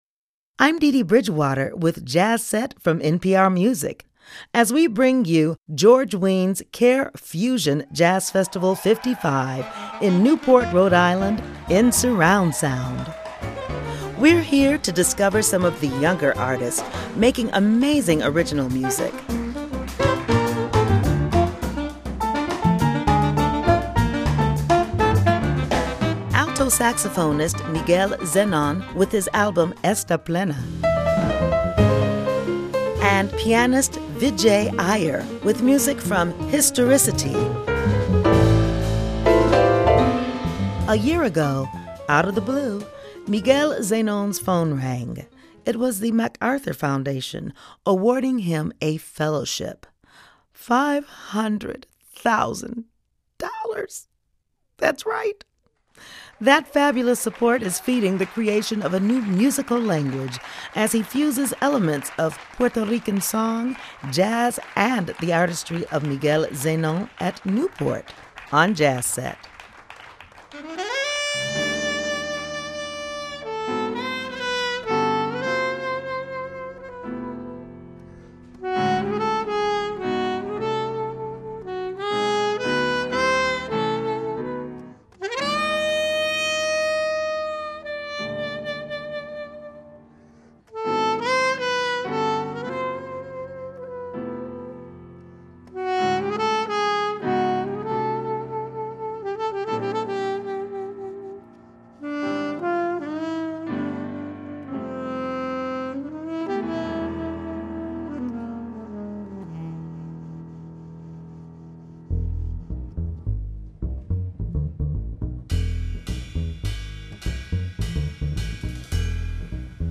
Saxophonist
in Surround Sound